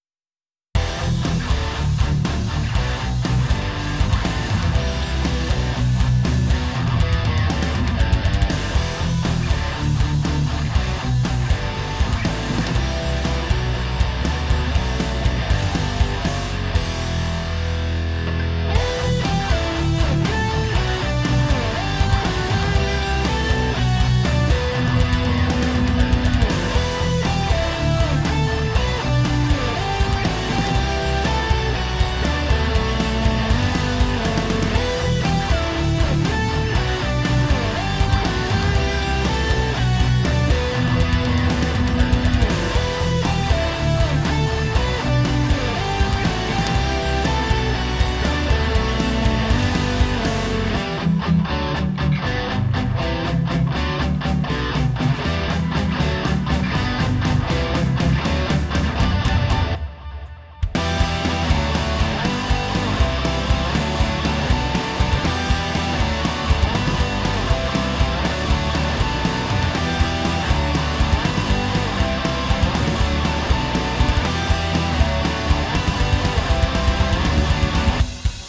(Varning för hårdrock, eller nåt) :)
Det är dock en multibandslimiter, och det gör ju bland annat att cymbalerna inte tar så mycket stryk av peakar i basen.
Det är ett crescendo från 0.50 -> 1.00 minuter.